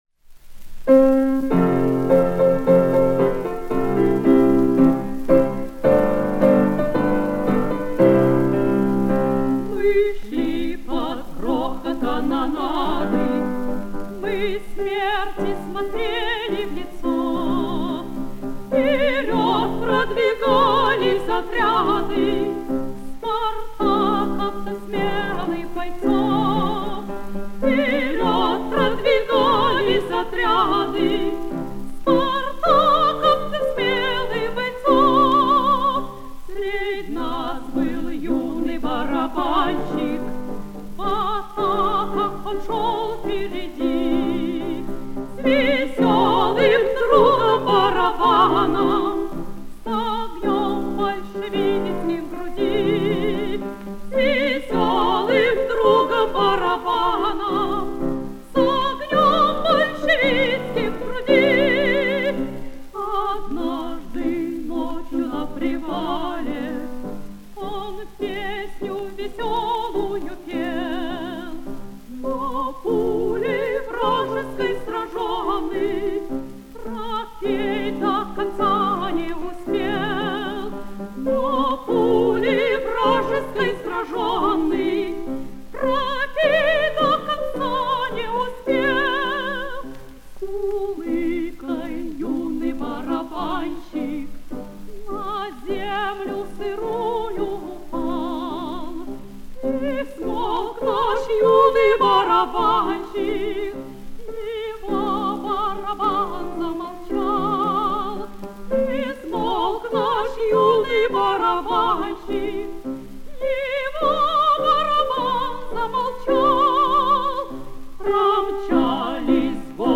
Запись 1960-х гг.
ф-но